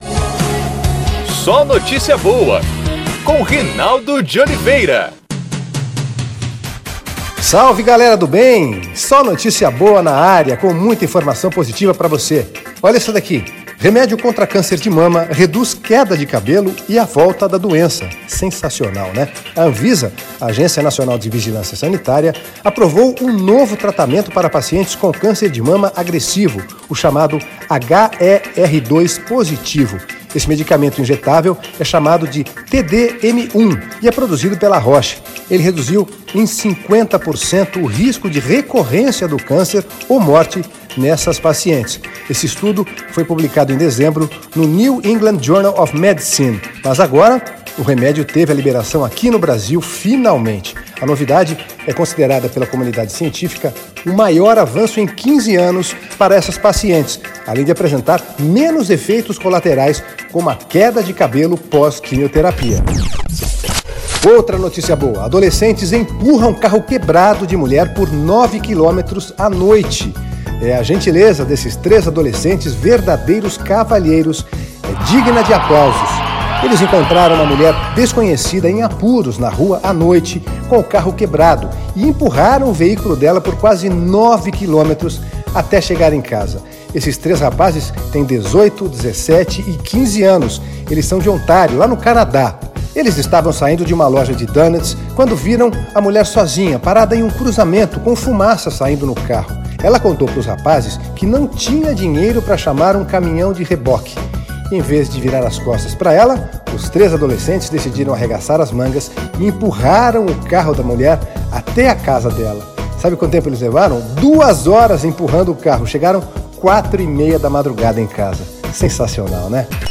O Podcast SNB também é exibido em pílulas na programação da Rádio Federal.